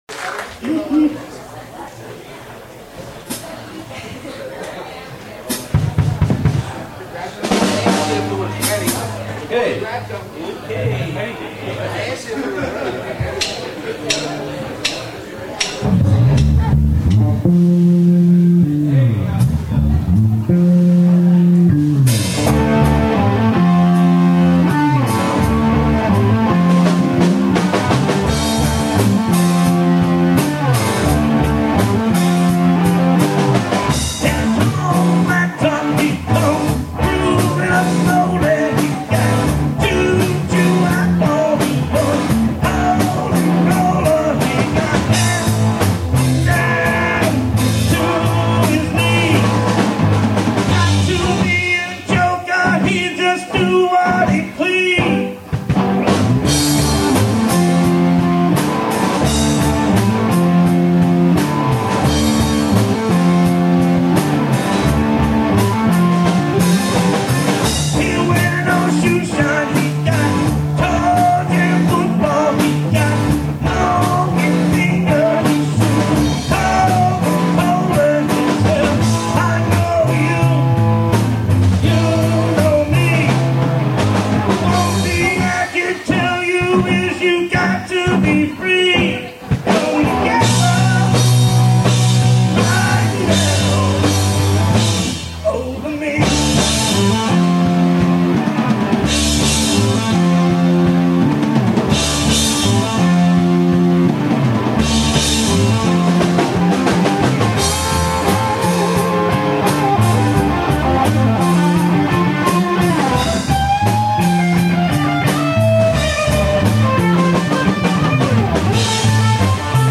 click here to listen to a song recorded at this show